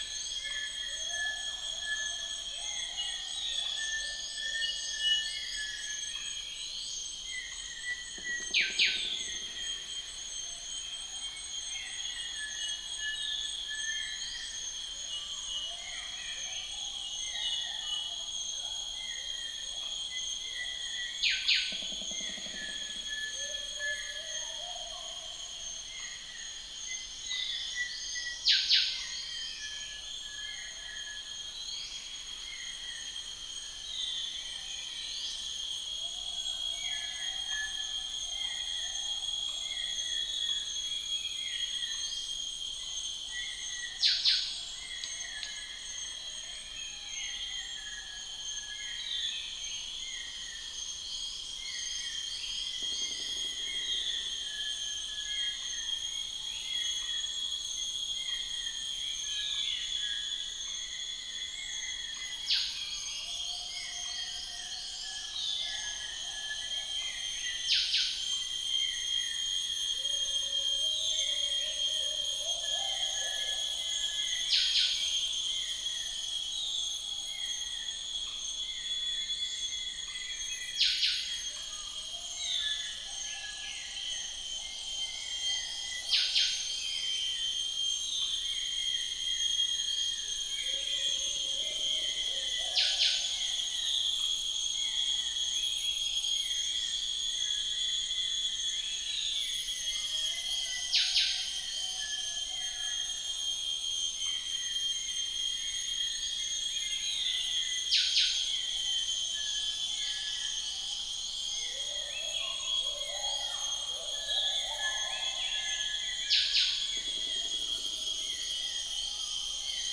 Psilopogon duvaucelii
Buceros rhinoceros
Dicrurus paradiseus
Pelargopsis capensis
Pycnonotus plumosus